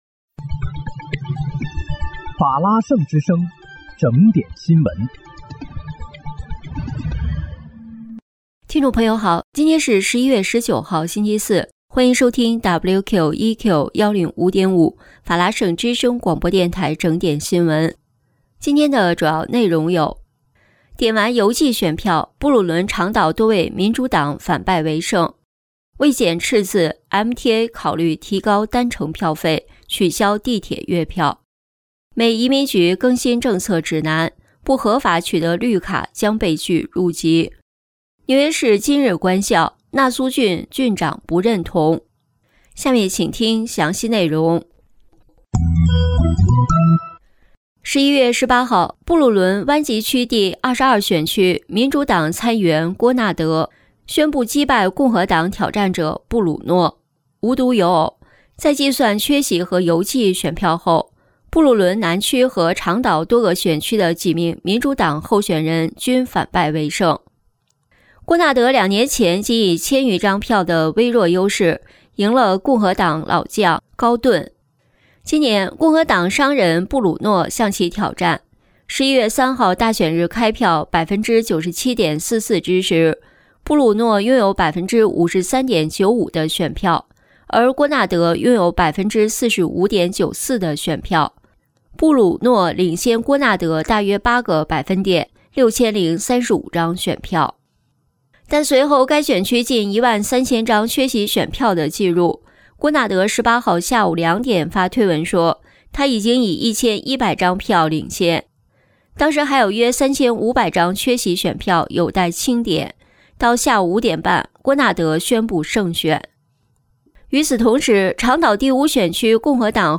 11月19日（星期四）纽约整点新闻